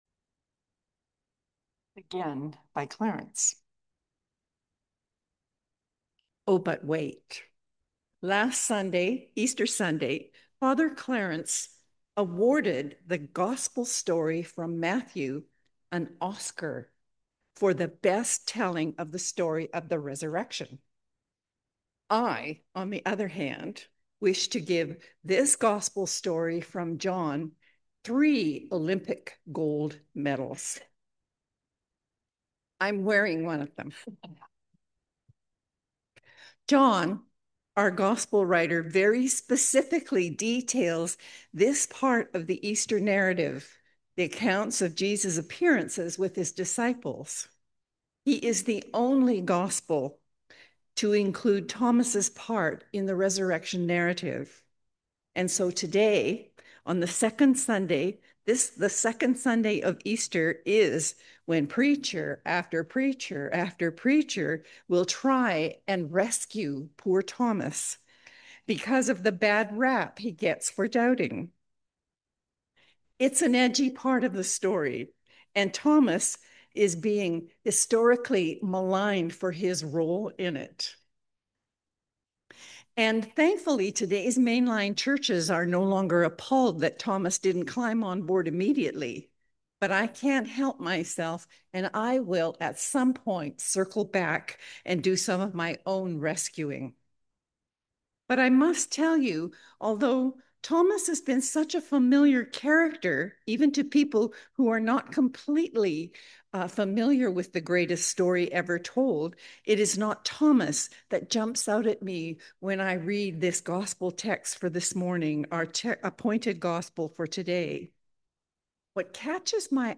Sermon on the Second Sunday of Easter